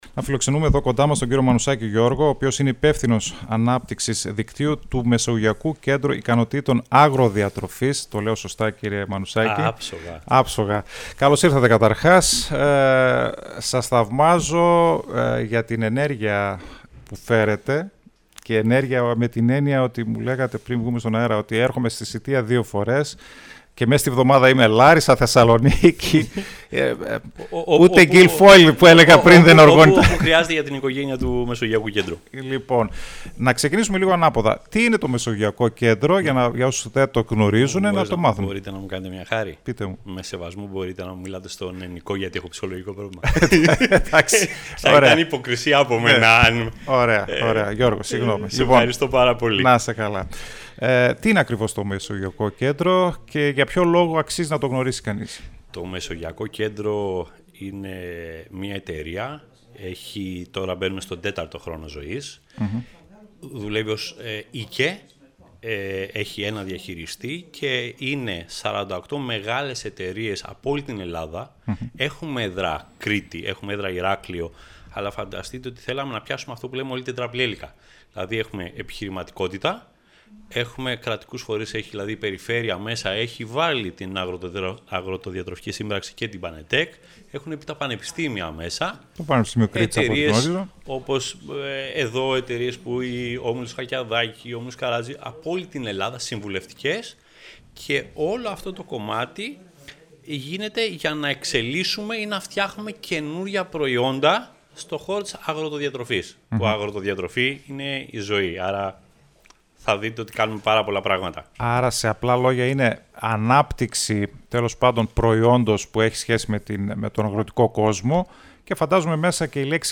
Την δράση και το σκοπό του Μεσογειακού Κέντρου Ικανοτήτων Αγροδιατροφής – MACC ανέλυσε στην πρωινή ζώνη του Style 100